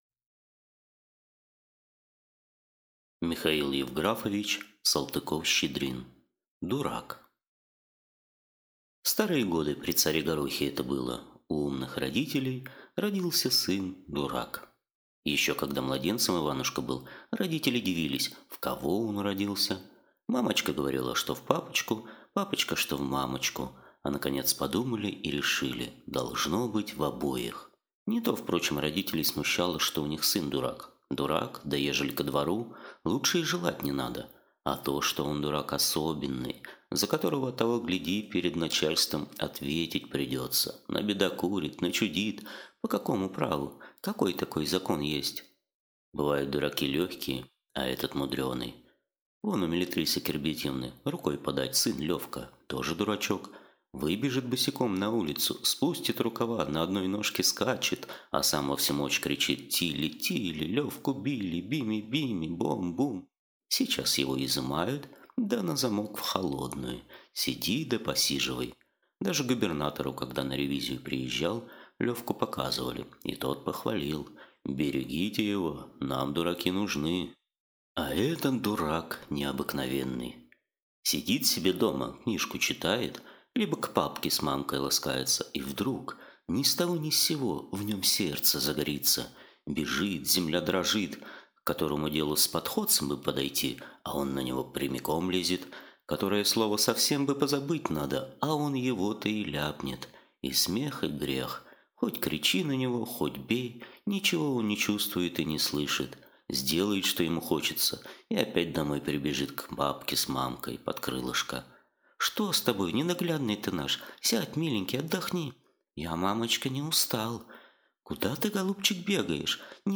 Аудиокнига Дурак | Библиотека аудиокниг